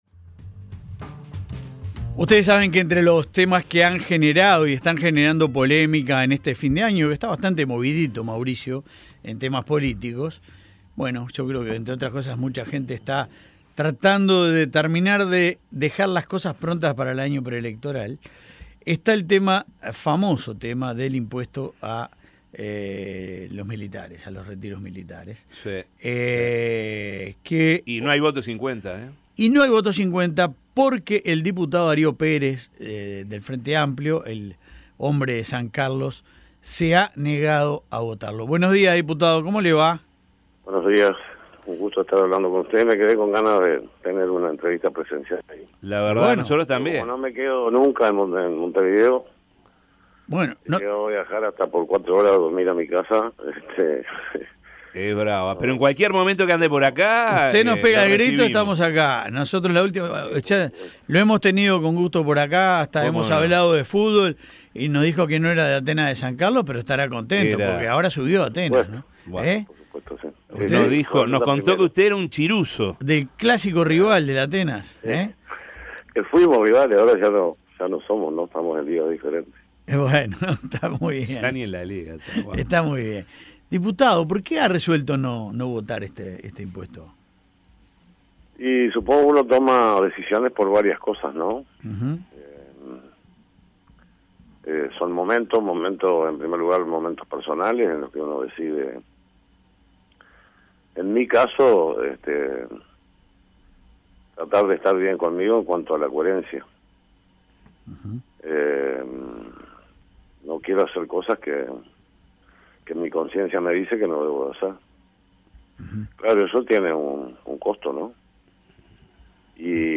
Escuche la entrevista de La Mañana: